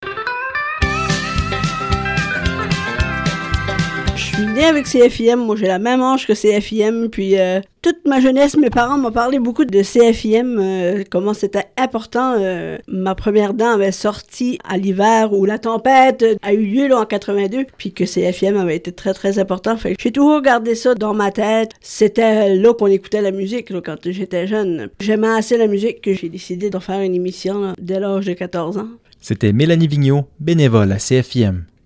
Dans le cadre du 44e Radiothon, les animateurs et animatrices bénévoles du Son de la mer ont été invités à témoigner de leur lien avec la radio communautaire des Îles et de que ça représente pour eux.